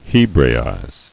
[He·bra·ize]